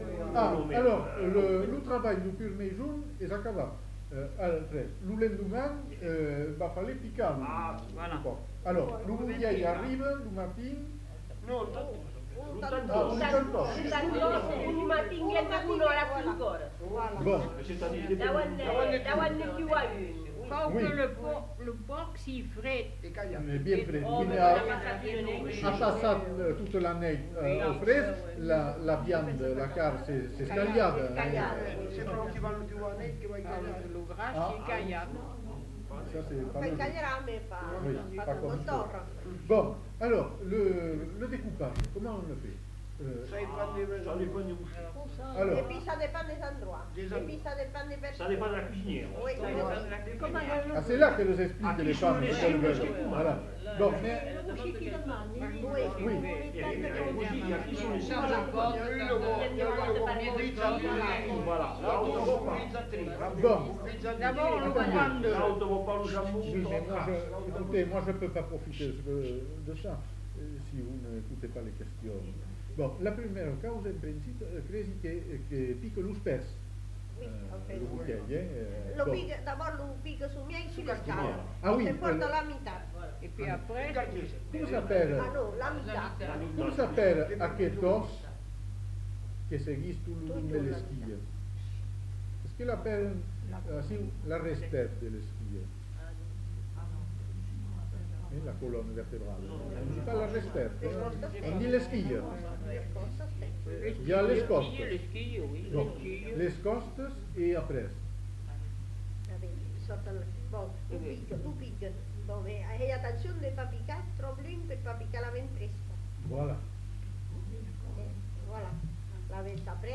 Lieu : Bazas
Genre : témoignage thématique Langue : occitan (gascon)